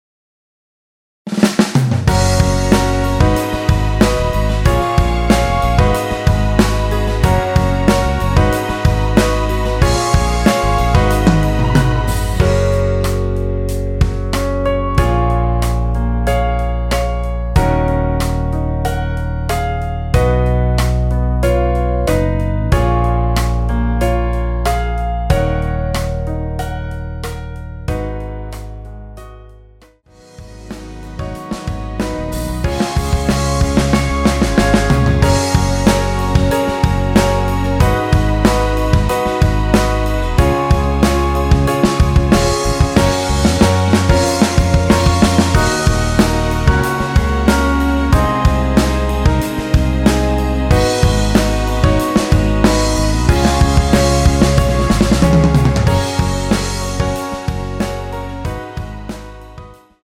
MR입니다.
여성분이 부르실 수 있는 키로 제작하였습니다(미리듣기 확인)
원키에서(+6)올린 MR입니다.
F#
앞부분30초, 뒷부분30초씩 편집해서 올려 드리고 있습니다.
중간에 음이 끈어지고 다시 나오는 이유는